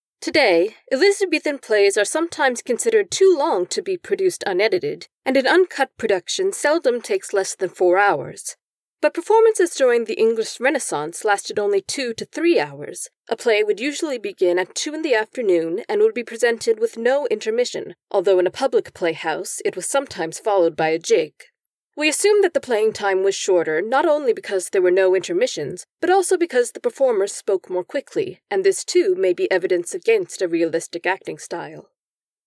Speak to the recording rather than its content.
I went through the standard editing levels for ACX submission, and while the ACX Check says that it meets the standards, it sounds like it’s clipping. This is on a Yeti Mic, and I’m using a pop filter. Also, I had to put the Noise Reduction to 18, 6, 6 to get rid of the whine/mosquito noise.